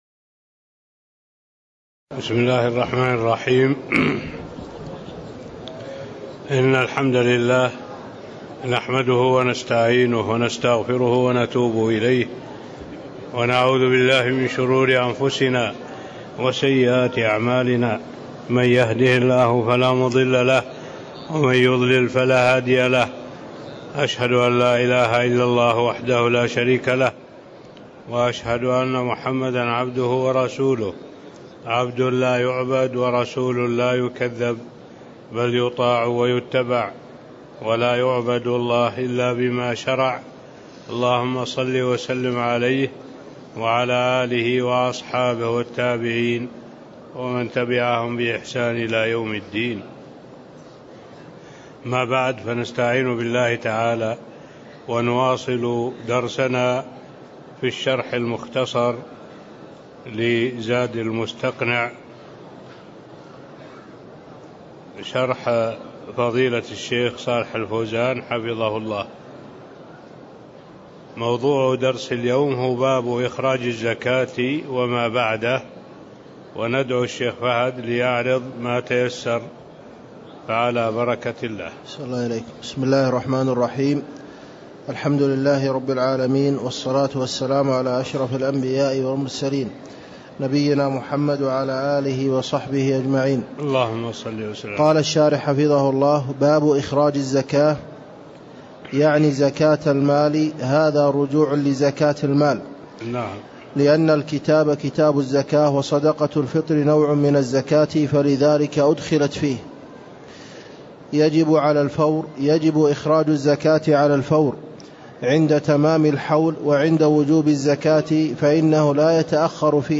تاريخ النشر ١٧ رجب ١٤٣٤ هـ المكان: المسجد النبوي الشيخ